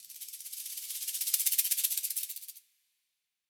shaker 1.0.wav